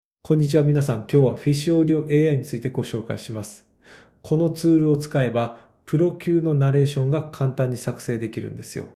2.1 テキスト読み上げ（Text-to-Speech）
テキストを入力するだけで、抑揚や感情表現まで自然な音声に変換。
このような文章も、まるでプロのナレーターが読み上げたかのような自然さで音声化できます。